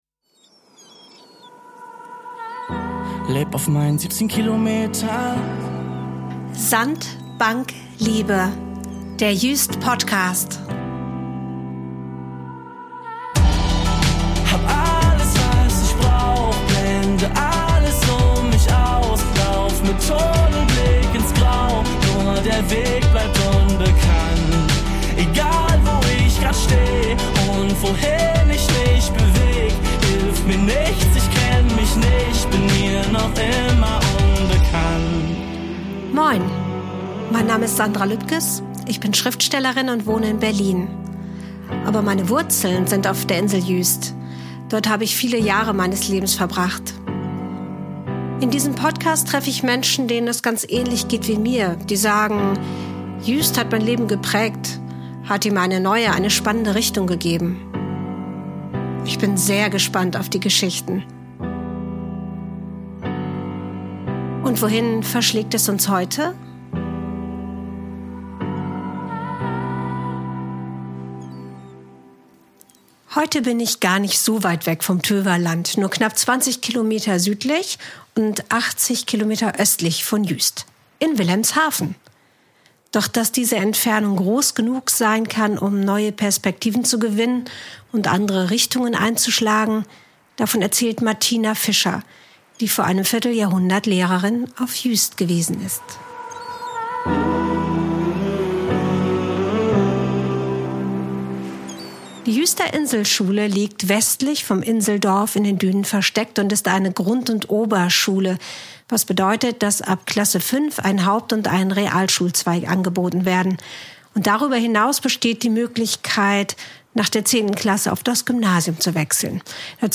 Strandgut-Musik während des Interviews